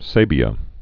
(sābē-ə)